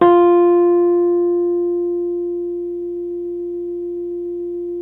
RHODES CL0CR.wav